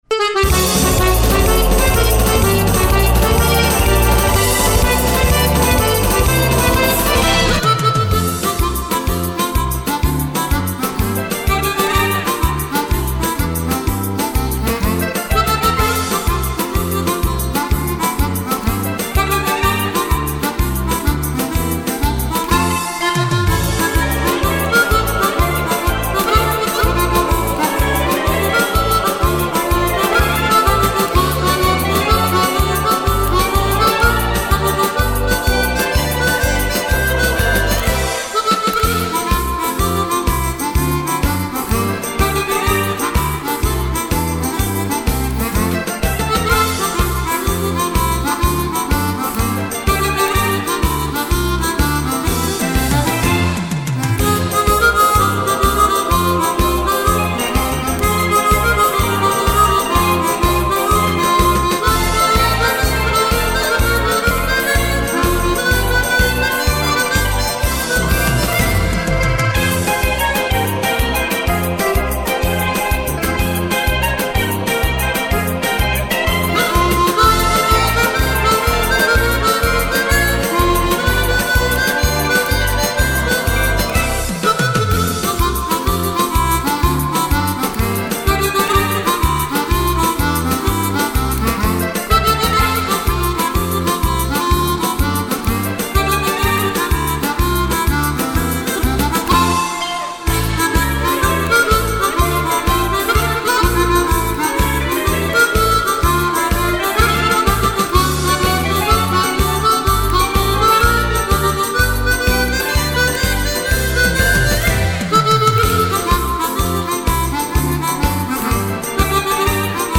version harmonica